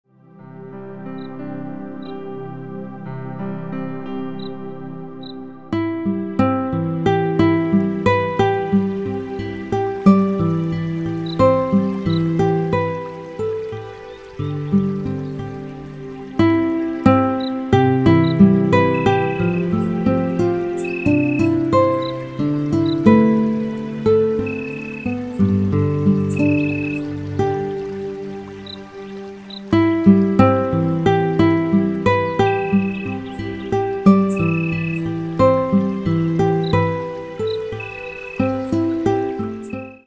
спокойные
природа , гитара , вода